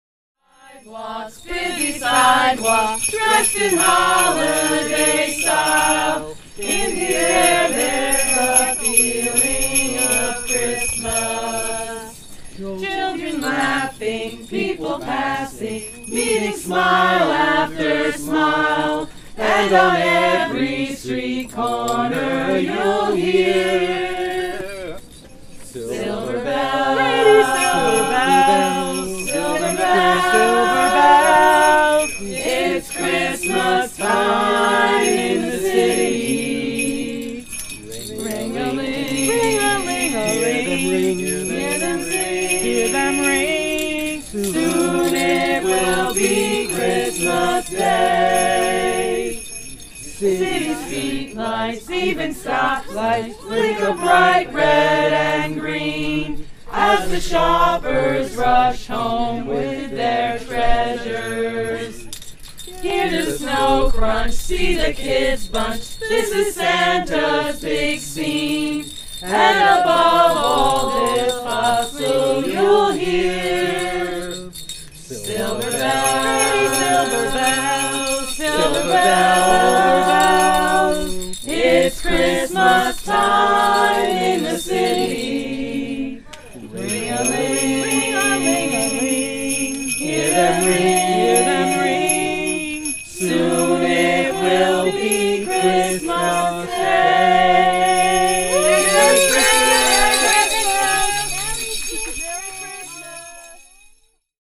Athens carolers: Silver Bells (Audio)